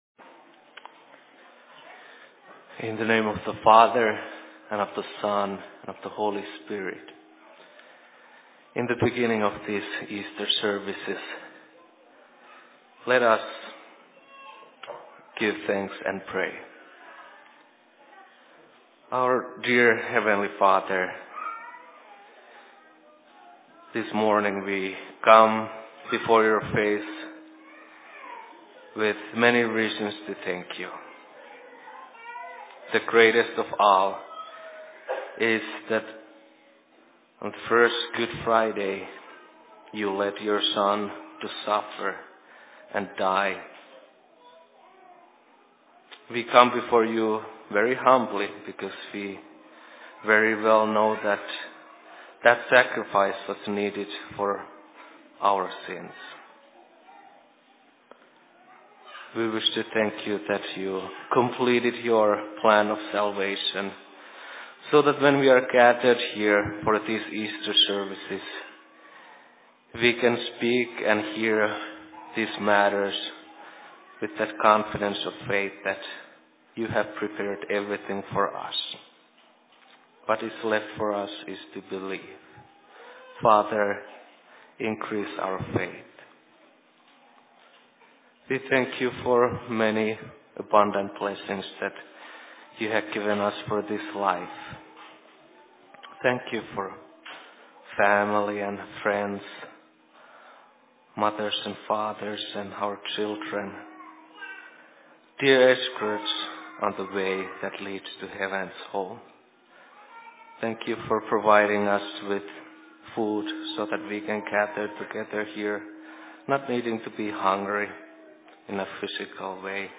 Sermon in Outlook 18.04.2014